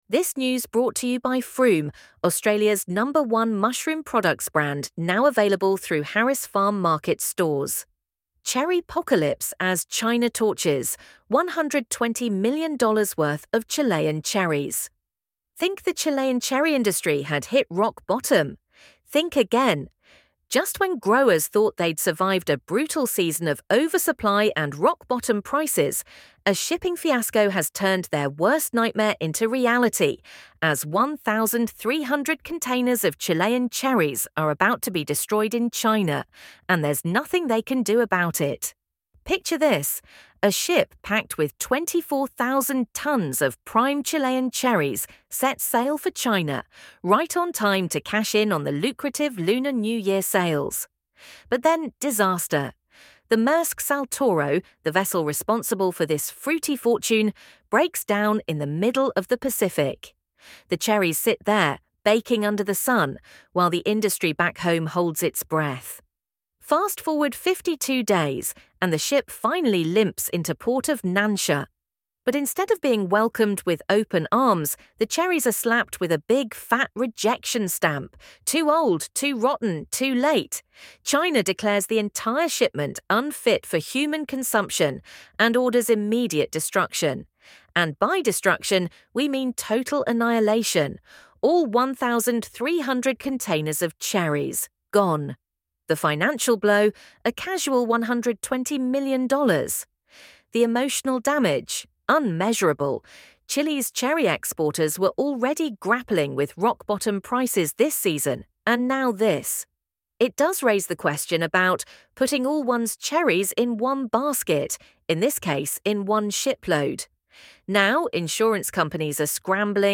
LISTEN TO THIS NEWS ARTICLE Think the Chilean cherry industry had hit rock bottom?